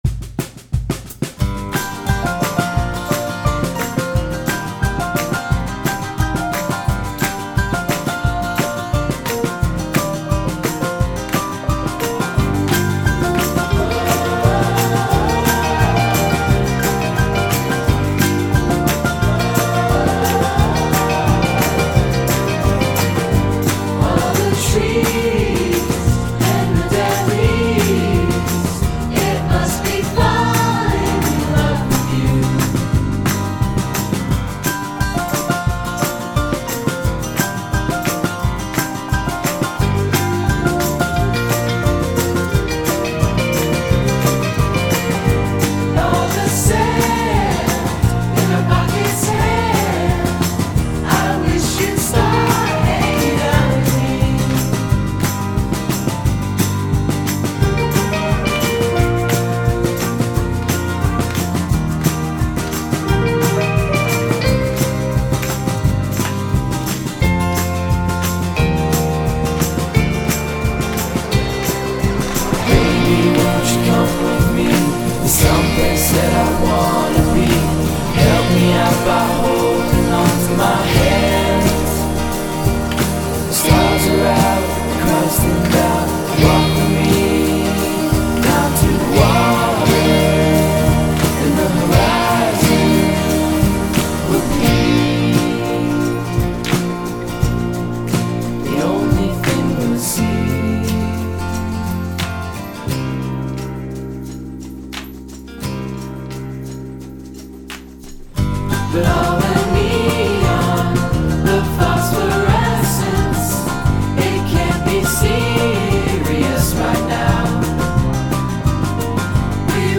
They put themselves in the genre of indie/folk.
-more folk-like